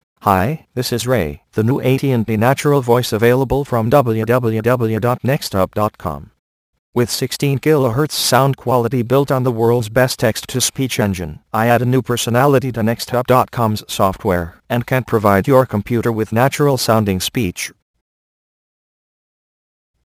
Texte de d�monstration lu par Ray (AT&T Natural Voices; distribu� sur le site de Nextup Technology; homme; anglais)